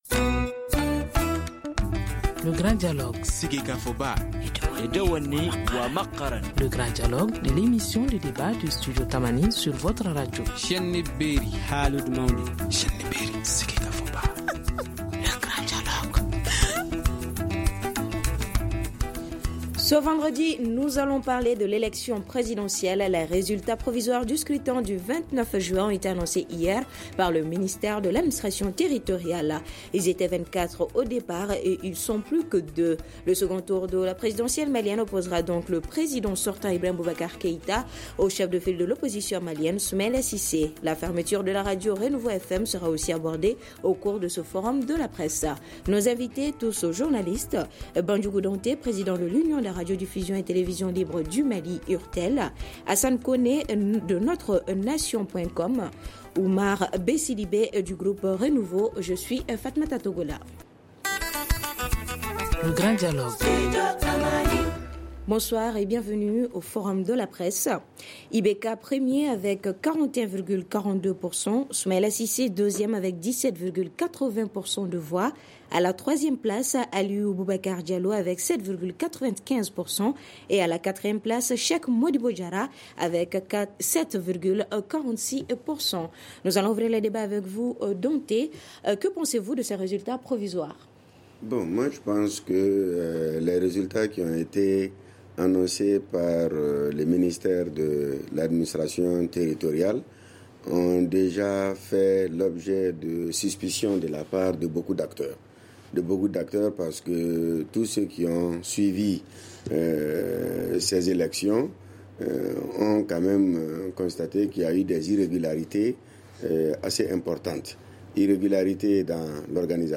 Nos invités (Tous Journalistes)